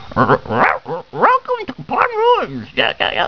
Bird sends greeting with sound
Click on the bird to hear it speak!!